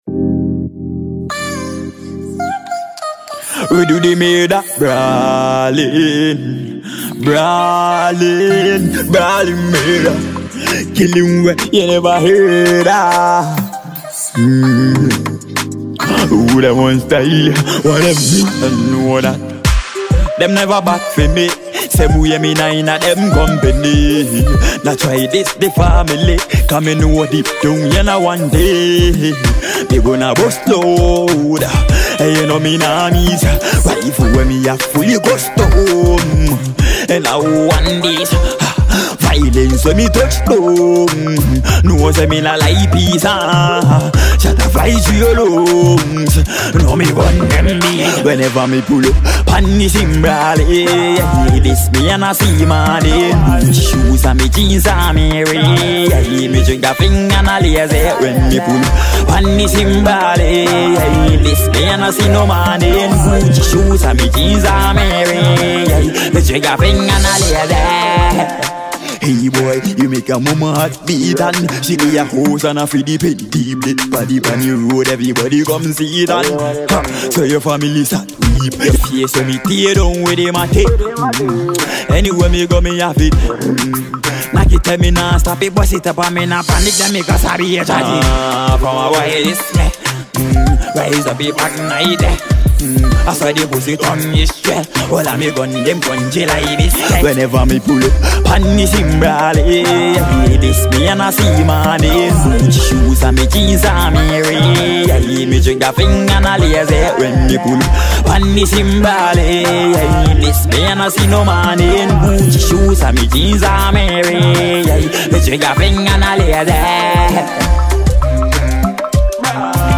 the prince of African dancehall